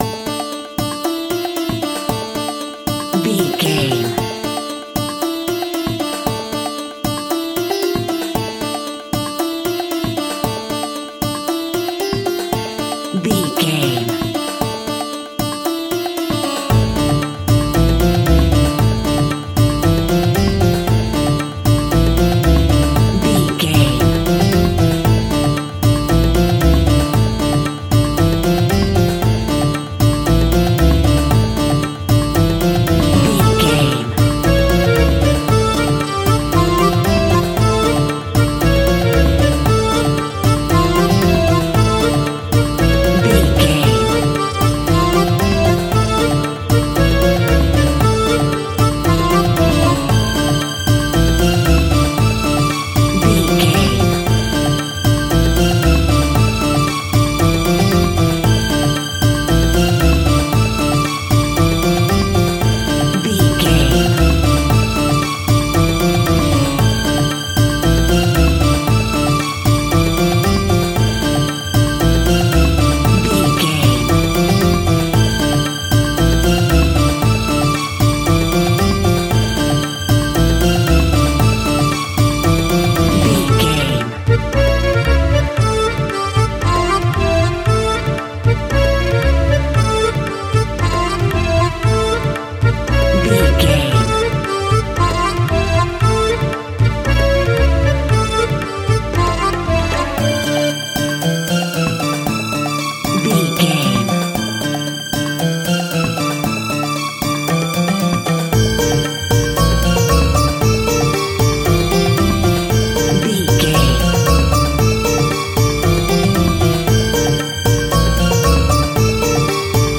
Aeolian/Minor
sitar
bongos
sarod
tambura